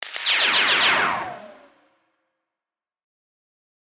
/ F｜演出・アニメ・心理 / F-30 ｜Magic 魔法・特殊効果
レーザー 4-Fastピュピュピューン